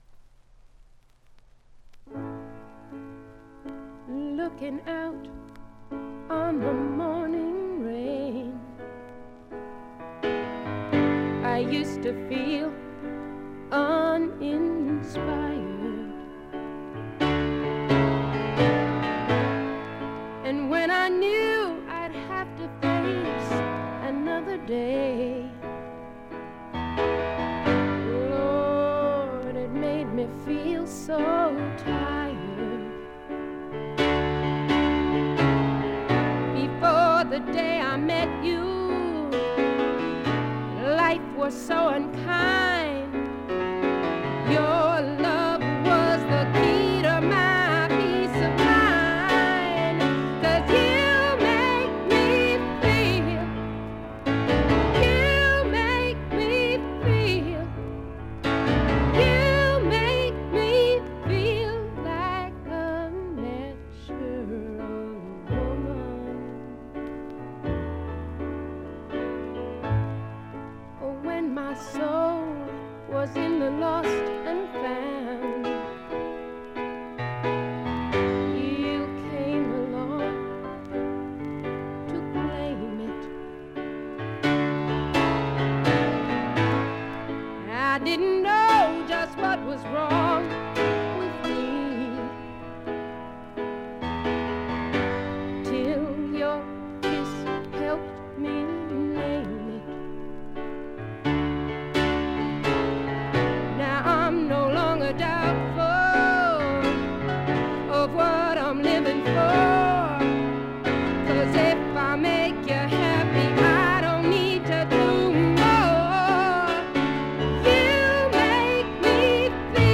静音部で軽微なバックグラウンドノイズ、チリプチ少々。
試聴曲は現品からの取り込み音源です。